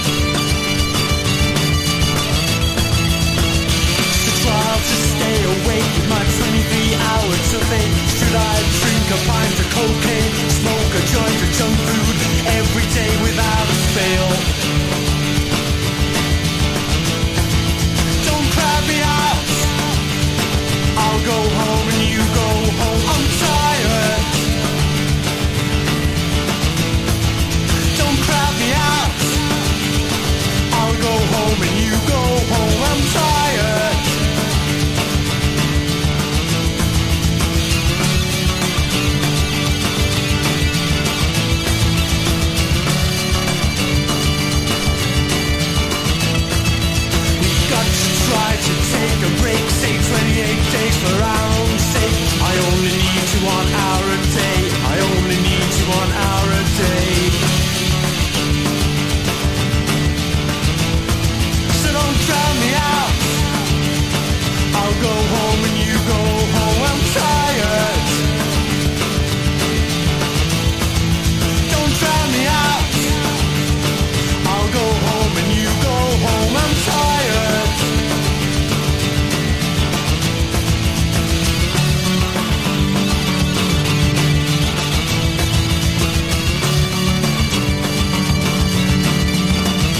1. 90'S ROCK >
メロディックな旋律 + 高音と低音を担うベースによる独特のサウンドがエバーグリーンを醸し出してます。